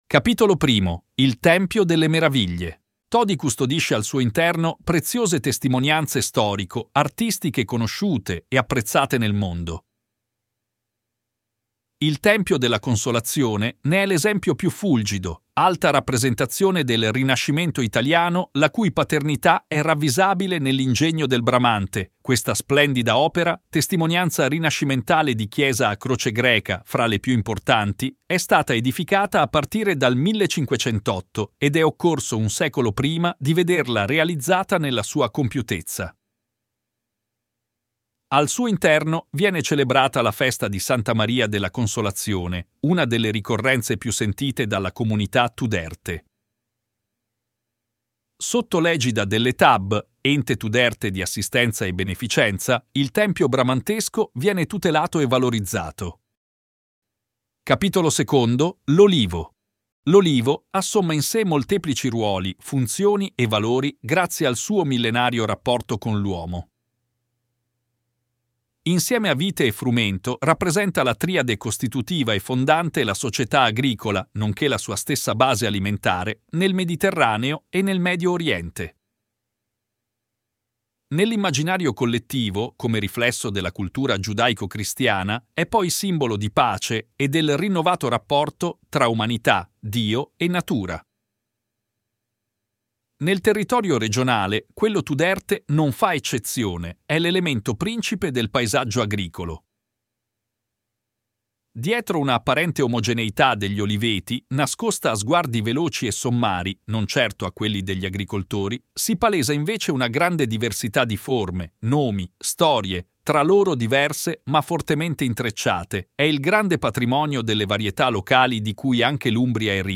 Audioguida